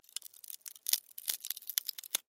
На этой странице собраны звуки термитов – от тихого шуршания до характерного постукивания.
Жук отгрызает часть древесины